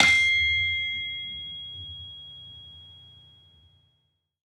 Gamelan Sound Bank
Saron-5-G#5-f.wav